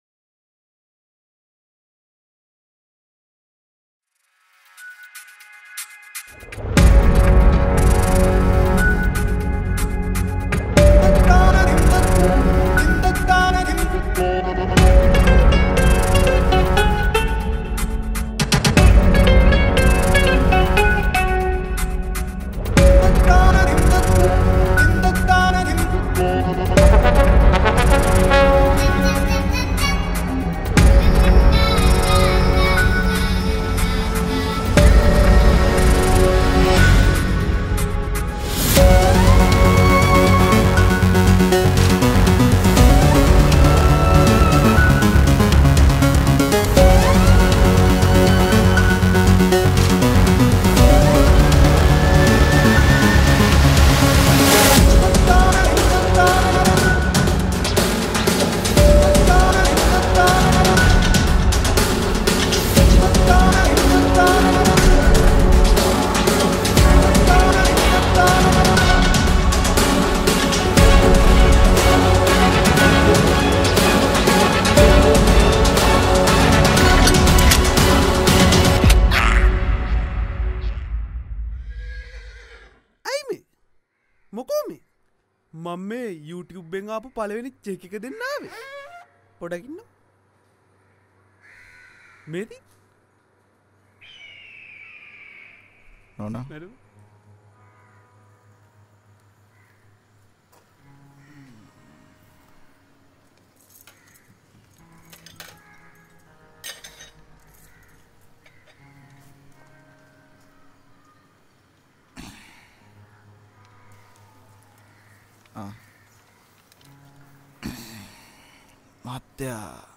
Rap song download.